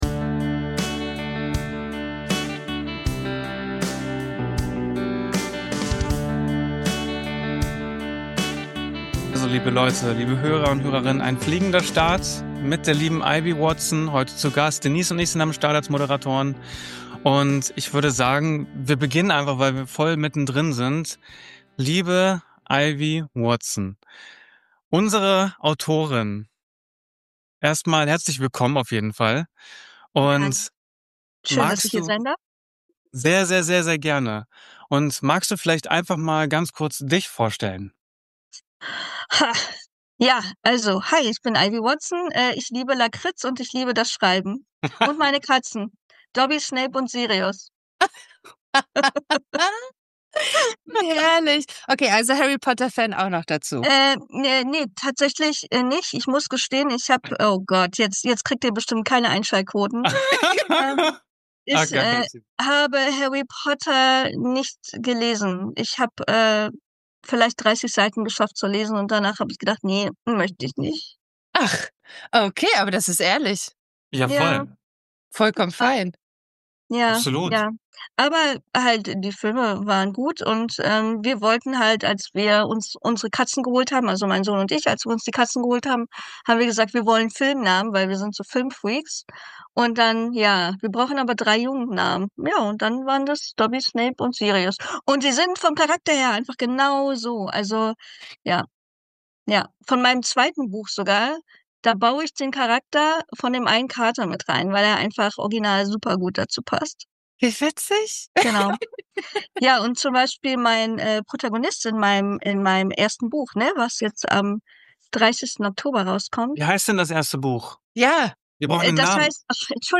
Ein Gespräch über Mut, Leidenschaft, Authentizität und die Kraft von Geschichten, die direkt aus dem Leben entspringen.